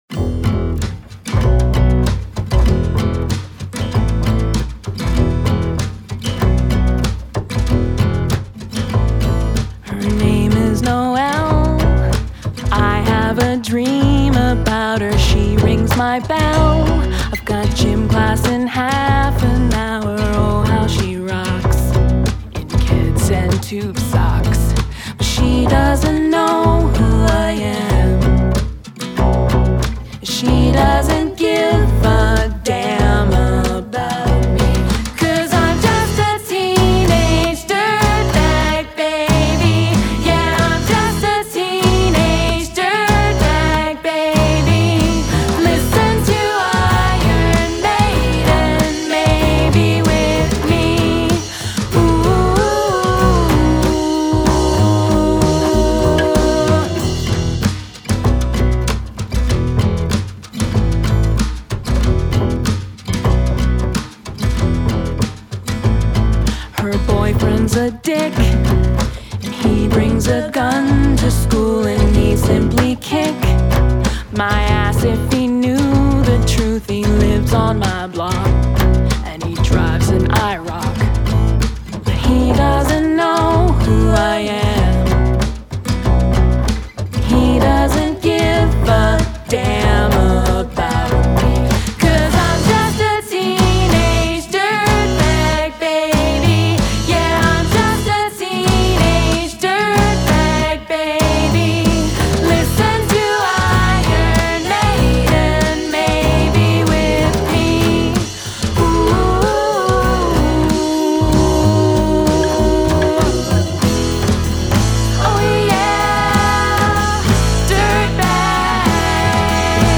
all-female acoustic roaming music act!
• 3-Piece: Female Vocals, Sax & Guitar
• Female Vocals
• Sax
• Guitar